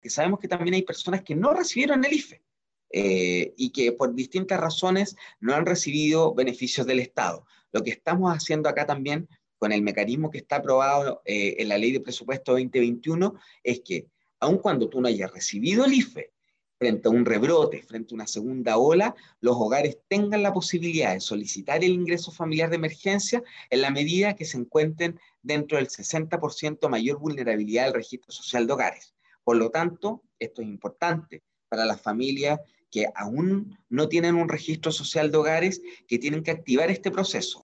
Sobre este eventual nuevo IFE, el subsecretario Villarreal destacó que es importante que las familias actualicen o completen su Registro Social de Hogares.